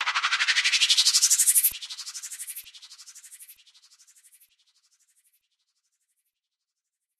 FX [Riser 2].wav